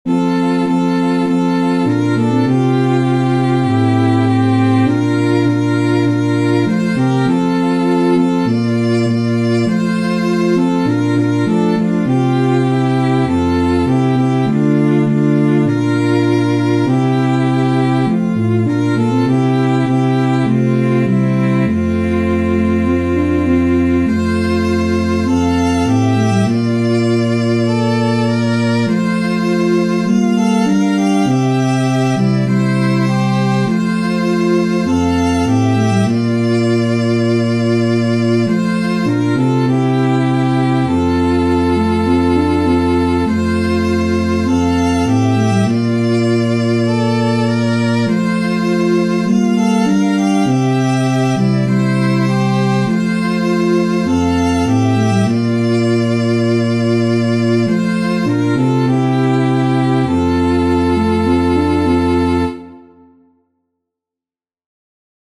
Chants de Noël Téléchargé par